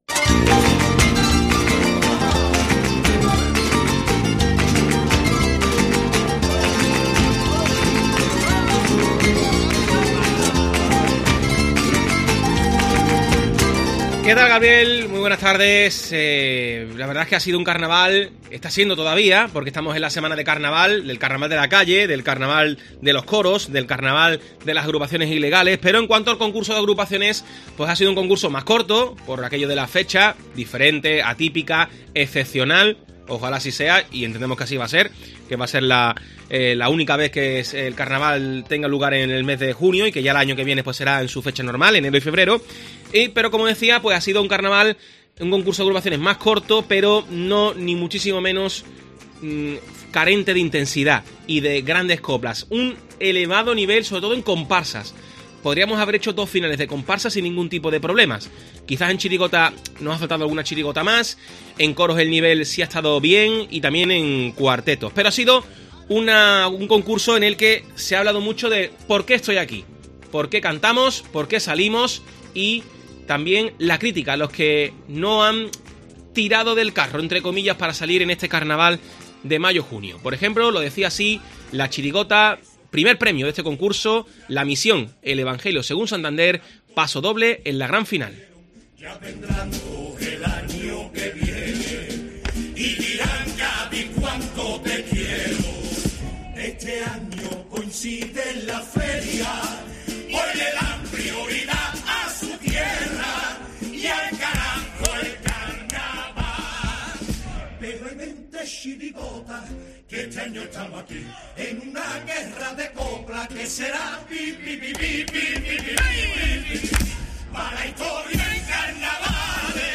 Resumen sonoro Carnaval de Cádiz 2022